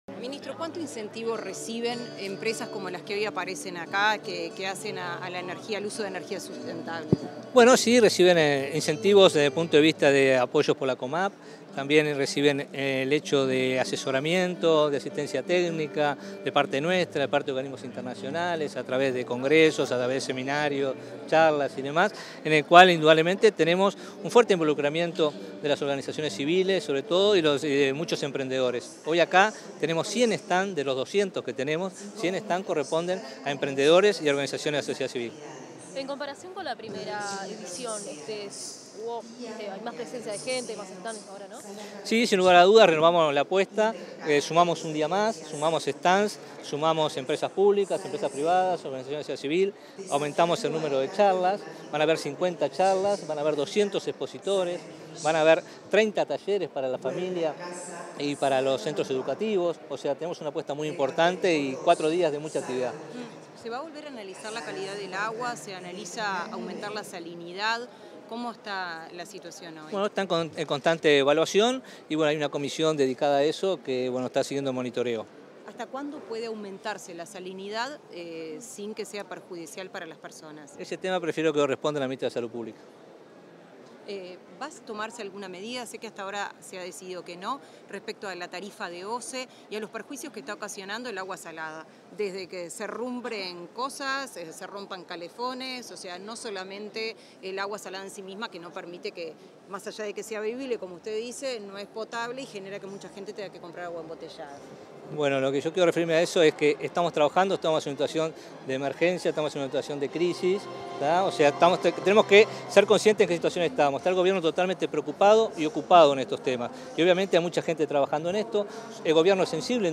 Declaraciones a la prensa del ministro de Ambiente, Robert Bouvier
Tras la apertura de la Expo Uruguay Sostenible, este 8 de junio, el ministro de Ambiente, Robert Bouvier, realizó declaraciones a la prensa.